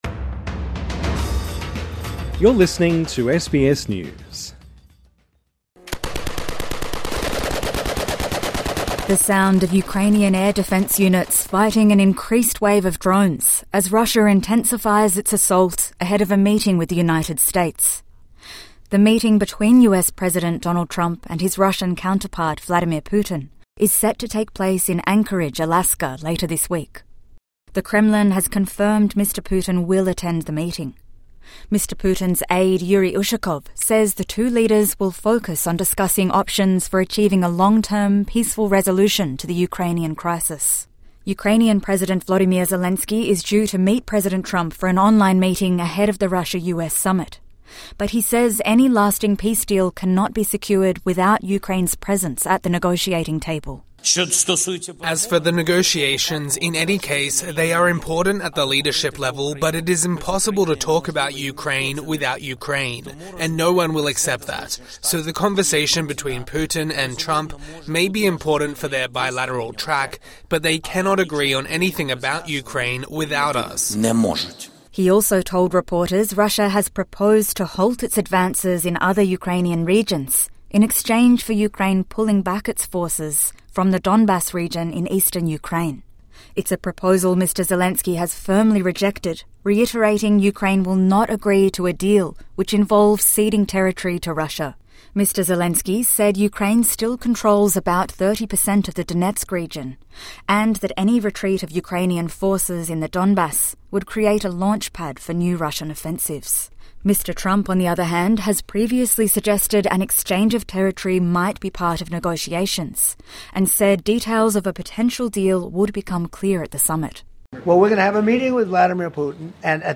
TRANSCRIPT (Sound of gunfire) The sound of Ukrainian air defence units fighting an increased wave of drones, as Russia intensifies its assault ahead of a meeting with the Unites States.